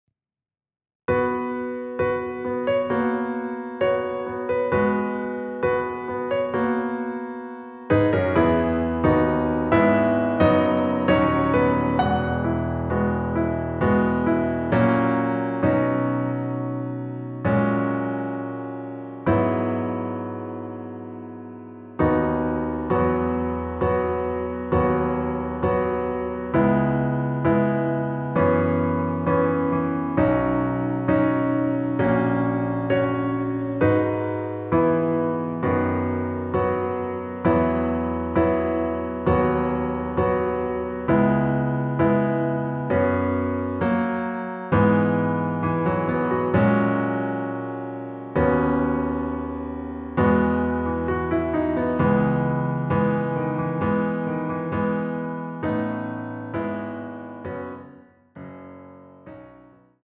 반주가 피아노 하나만으로 되어 있습니다.(미리듣기 확인)
원키에서(-2)내린 피아노 버전 MR입니다.
앞부분30초, 뒷부분30초씩 편집해서 올려 드리고 있습니다.
중간에 음이 끈어지고 다시 나오는 이유는